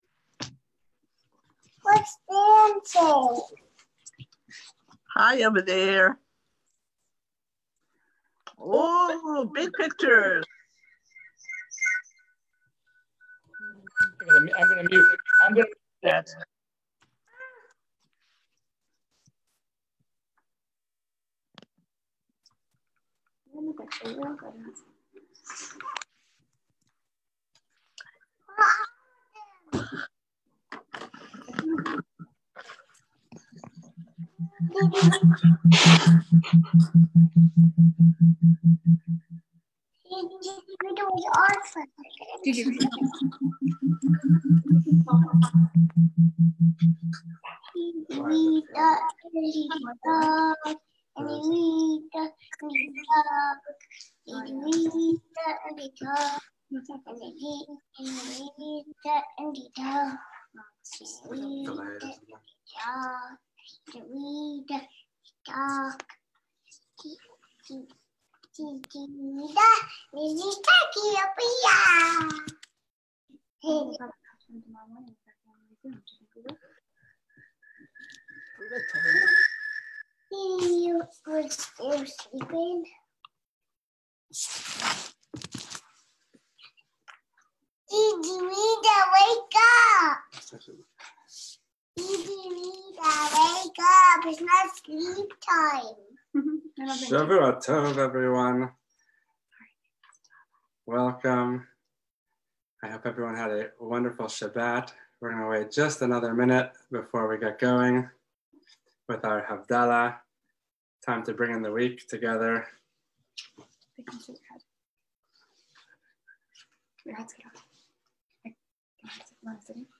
Shavua tov everyone!! Let's bring the week in together with joy and song.